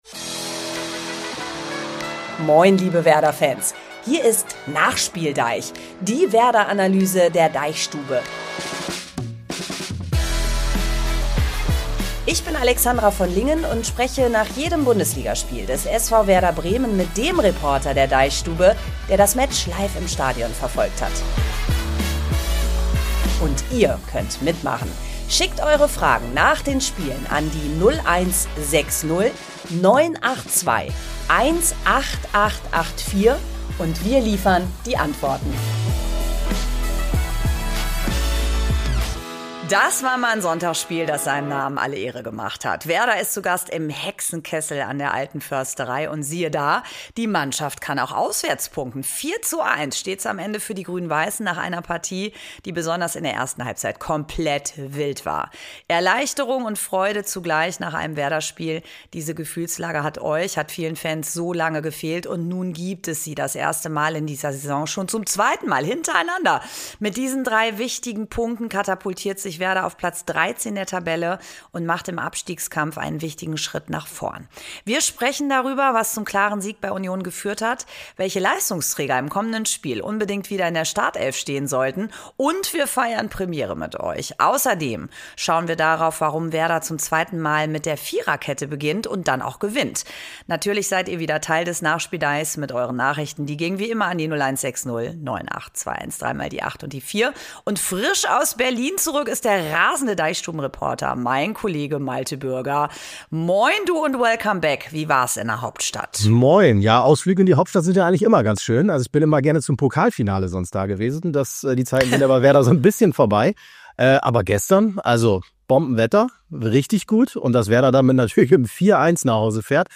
NachspielDEICH ist ein Fußball-Podcast der DeichStube.